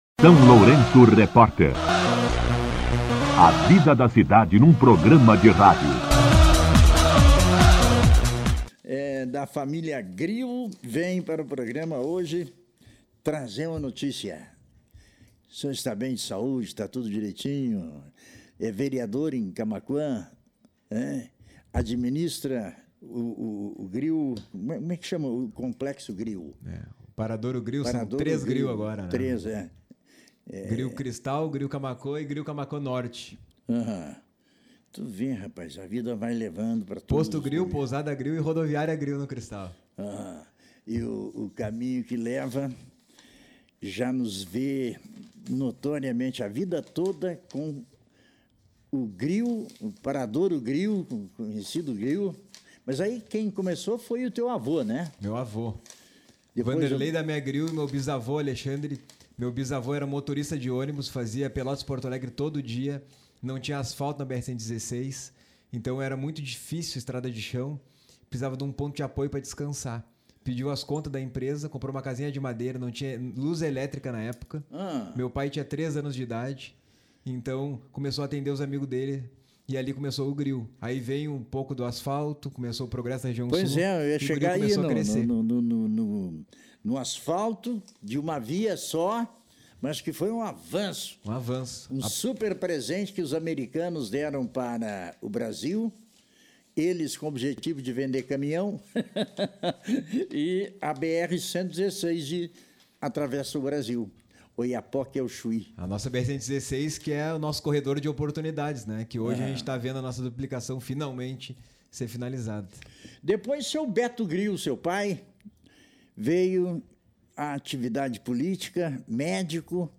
Entrevista com João Pedro Grill, vereador em Camaquã